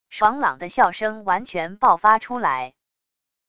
爽朗的笑声完全爆发出来wav音频